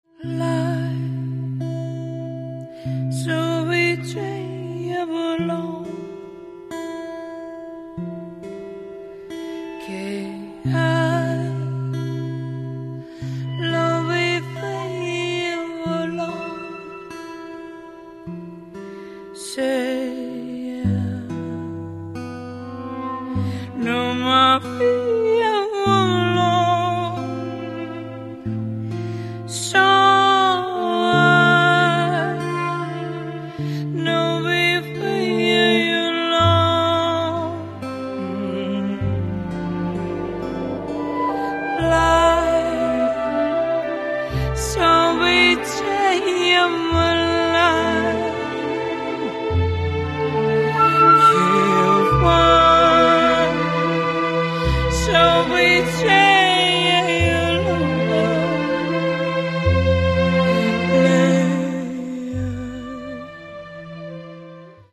Каталог -> Джаз и около -> В Украине